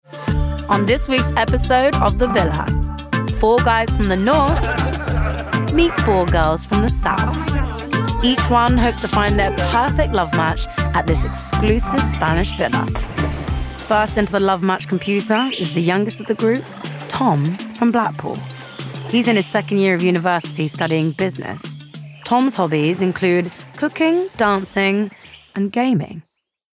London, RP ('Received Pronunciation')
Presenter Documentary Narration